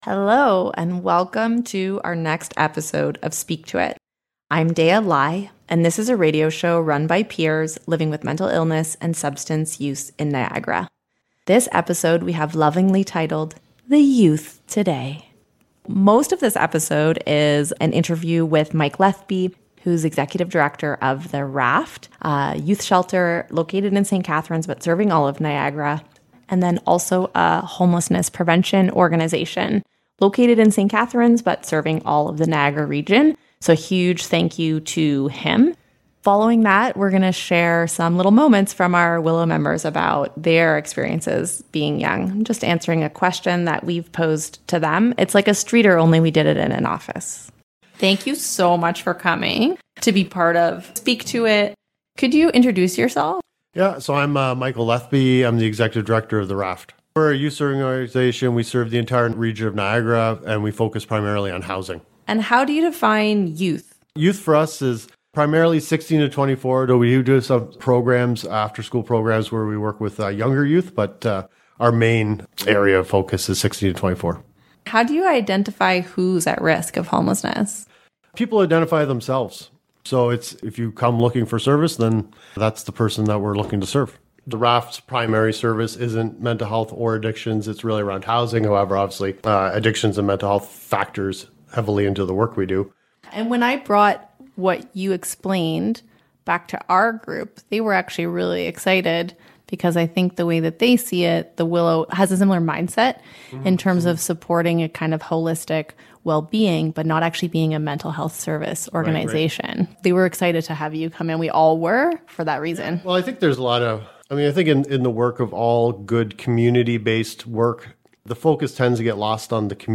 Speak To It A show run by peers living with mental illness and/or substance use in Niagara.